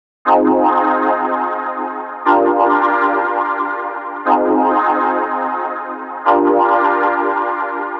Bp Pads Loop.wav